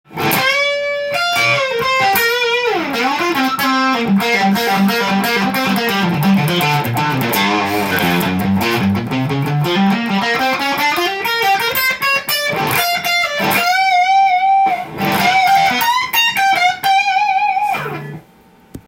フェンダーのジャズマスターです！
歪ませると音がとても伸びました！